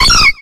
infinitefusion-e18 / Audio / SE / Cries / CLEFFA.ogg